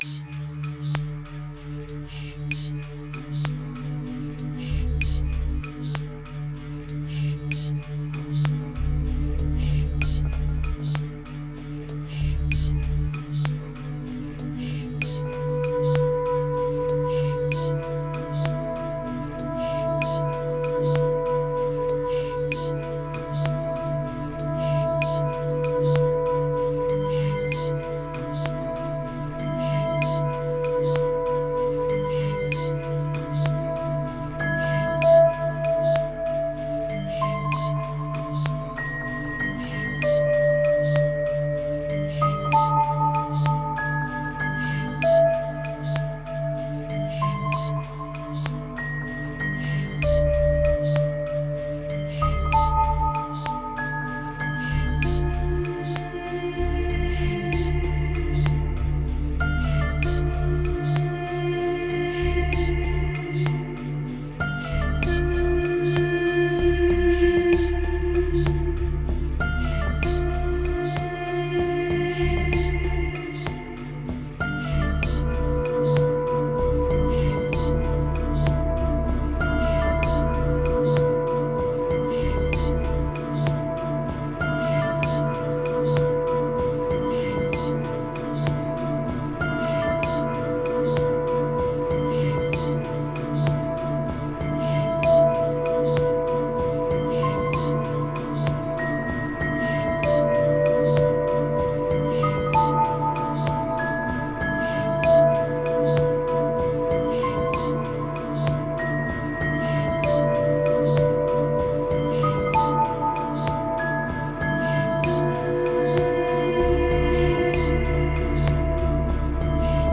596📈 - 92%🤔 - 48BPM🔊 - 2021-06-25📅 - 714🌟